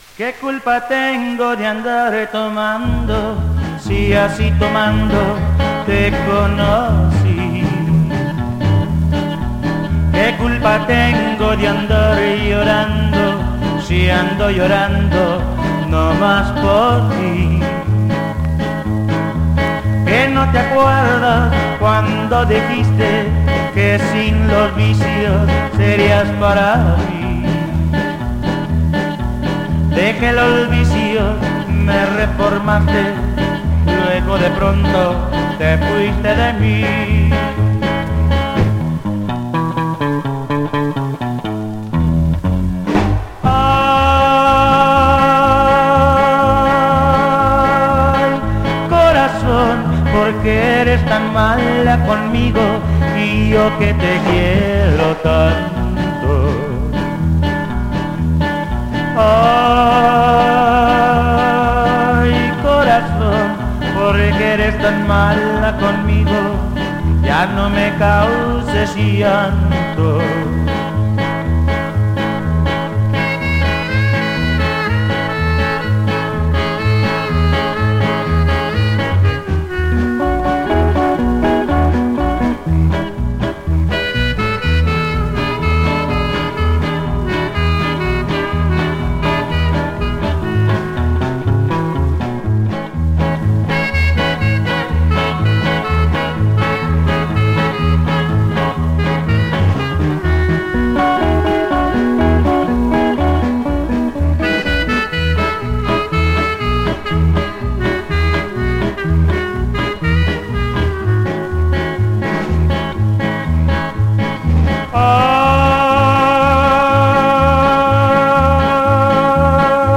super rare tejano roots music